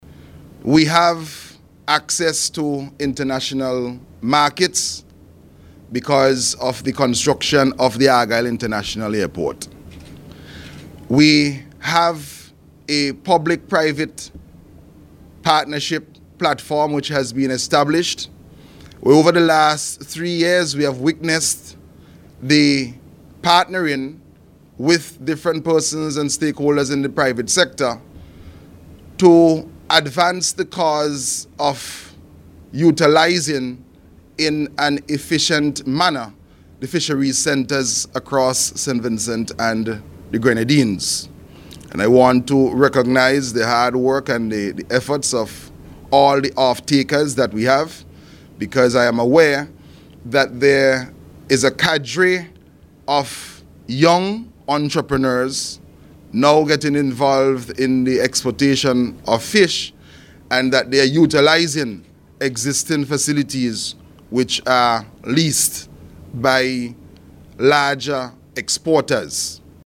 Minister of Agriculture, Forestry and Fisheries, Saboto Caesar was present at the hand-over ceremony at the Fisheries Conference Room.